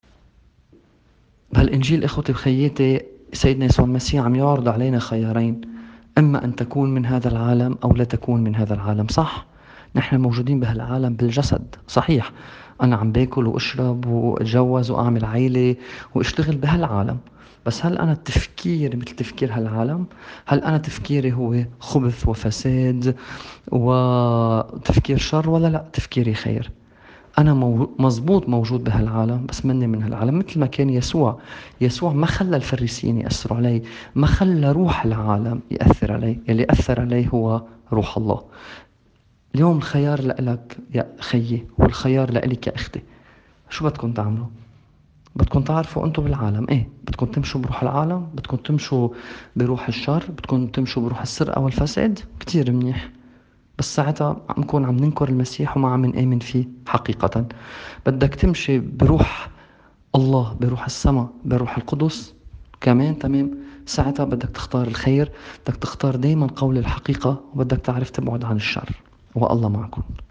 تأمّل في إنجيل يوم ١٦ أيلول ٢٠٢٠.mp3